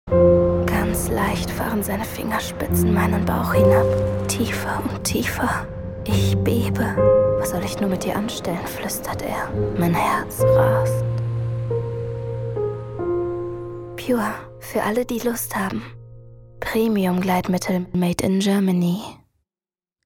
Eigenes Studio mit Schallkabine, Neumann-Mikrofon.
Kein Dialekt
Sprechprobe: Werbung (Muttersprache):